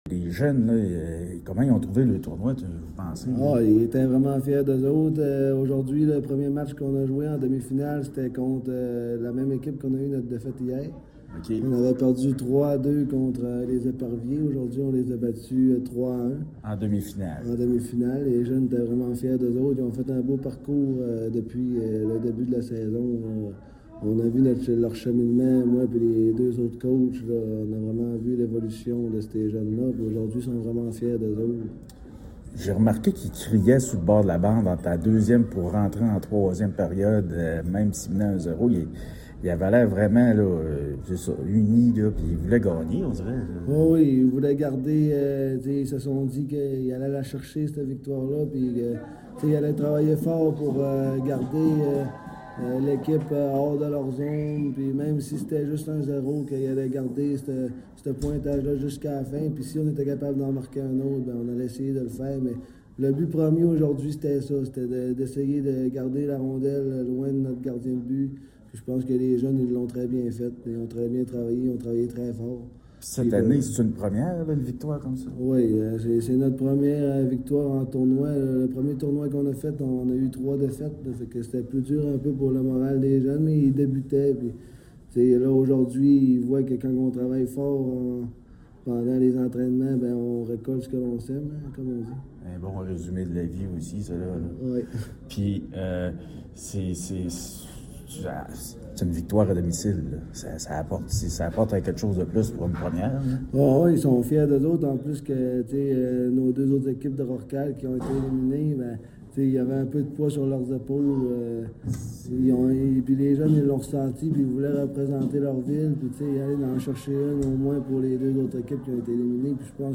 en entrevue.